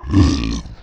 AlienHit.wav